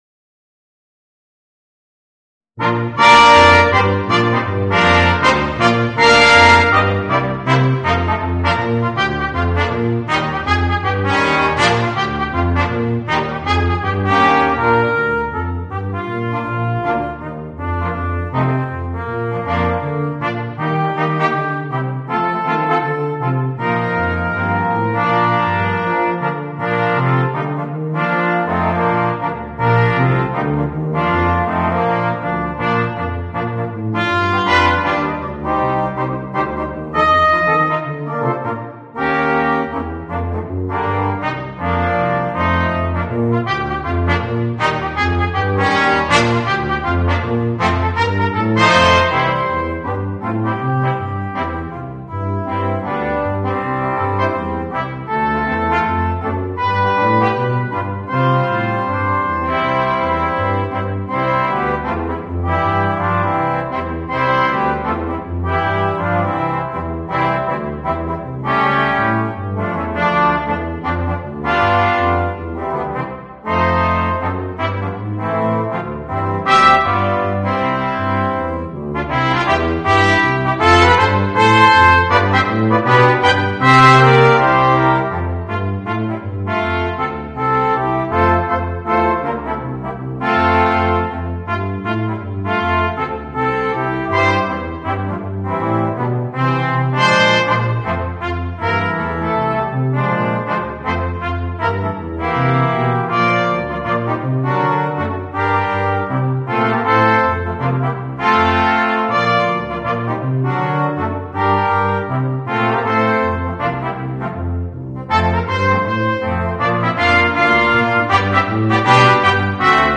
Voicing: 3 Trumpets, Trombone and Tuba